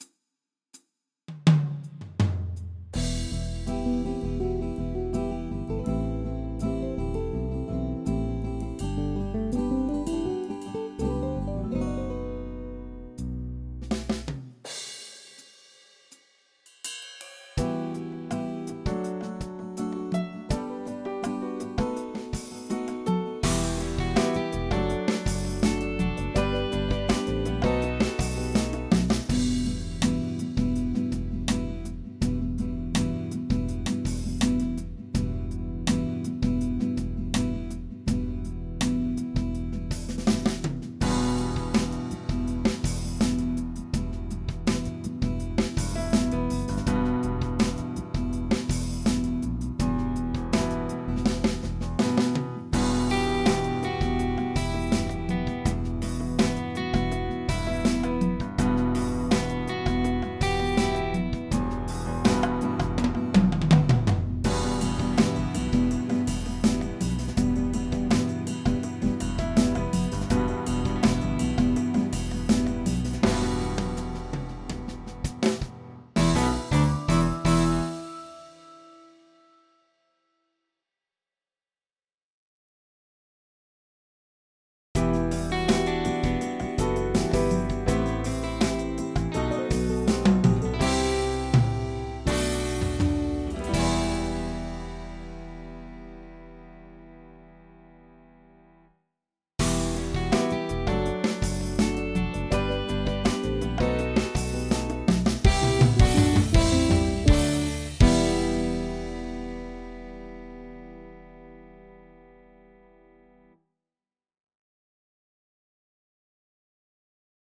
Example - style from a ketron sd1  16-Beat-3 in a tonality E minor(Emol)
I have just recorded for you this style with the SF2 that was on my folder (Only Live drums are sampled from Ketron)
I think the guitar sounds exactly like on any Ketron keyboard.
16BEAT3.mp3